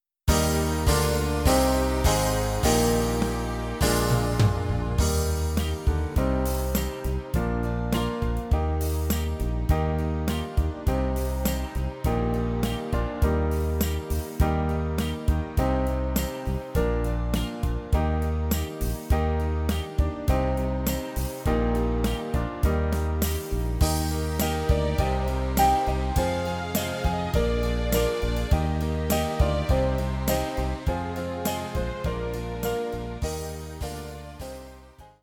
Gattung: Weihnachtsmusik für Trompete (inkl. Audiodatei)
Besetzung: Instrumentalnoten für Trompete